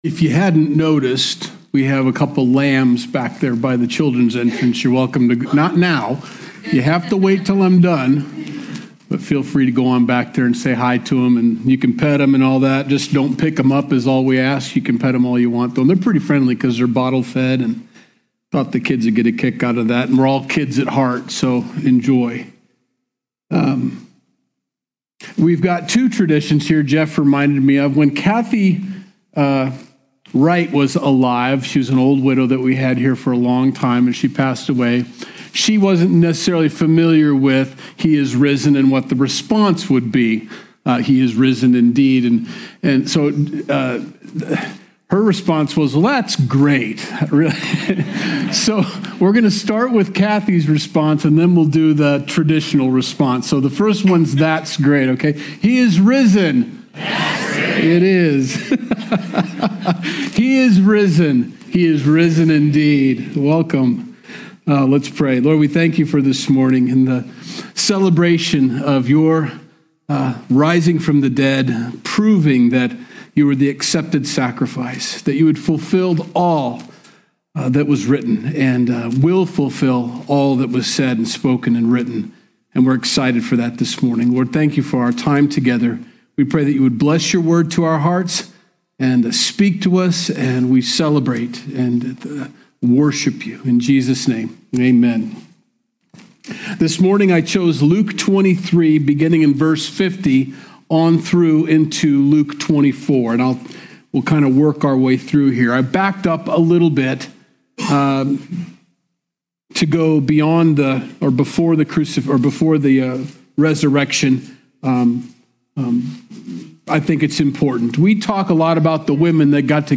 Sunday Message - March 2nd, 2025